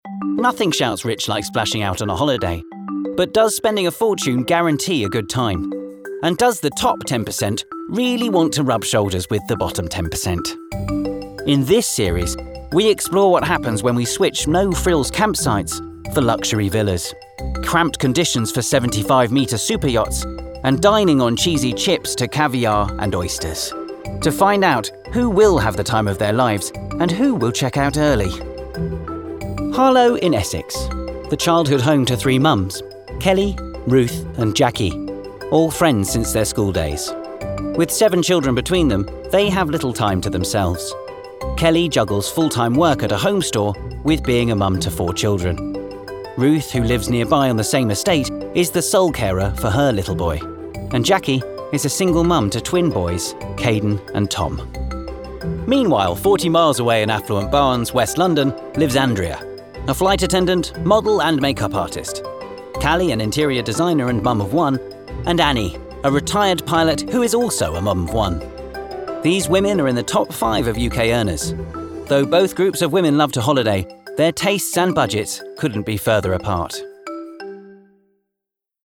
Voice Reel
Narration